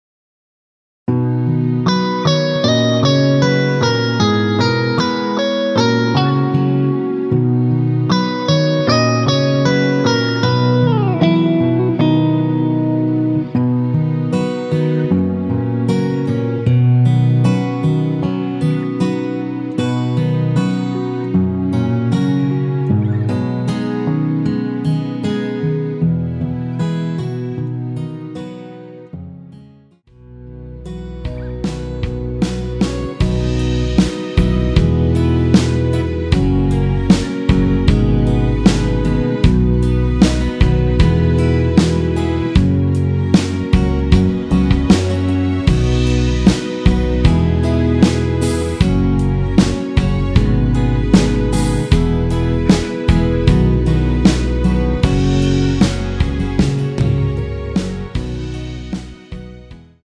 음정은 반음정씩 변하게 되며 노래방도 마찬가지로 반음정씩 변하게 됩니다.
앞부분30초, 뒷부분30초씩 편집해서 올려 드리고 있습니다.